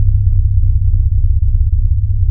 SUBBASS.wav